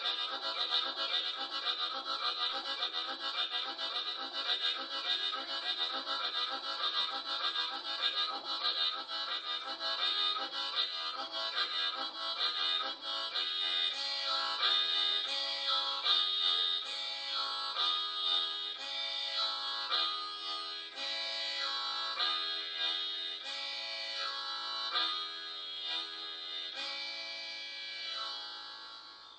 5th OTS Recital - Winter 2005